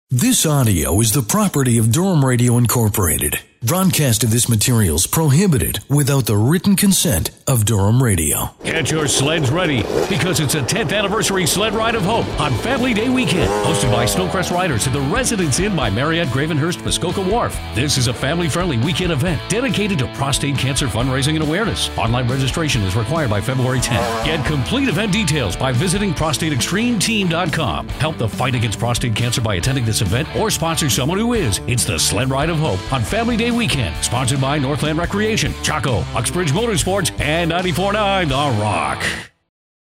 2014 The Dock Sled Ride Radio Ad